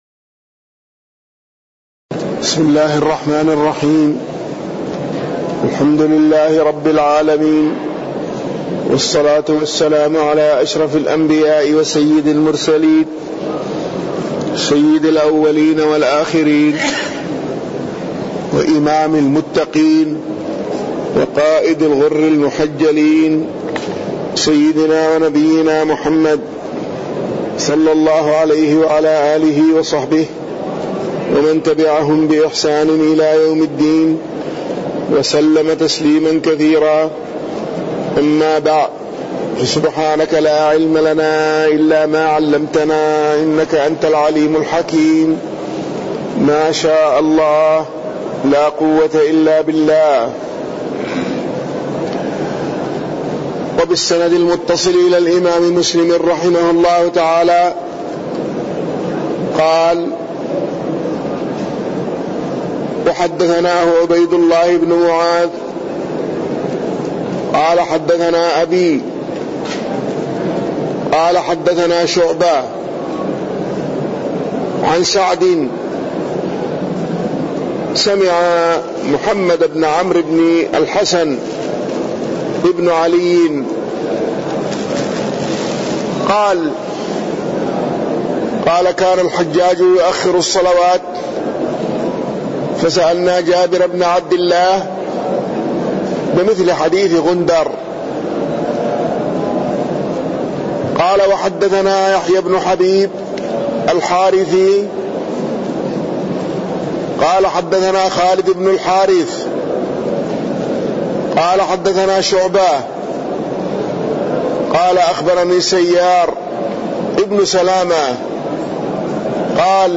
تاريخ النشر ٢٠ صفر ١٤٣٠ هـ المكان: المسجد النبوي الشيخ